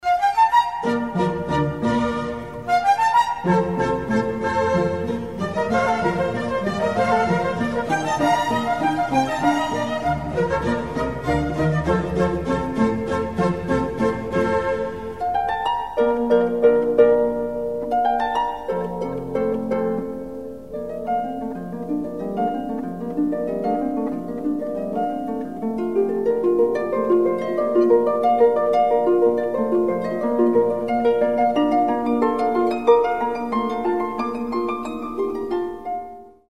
• Качество: 320, Stereo
красивые
мелодичные
спокойные
без слов
скрипка
инструментальные
classic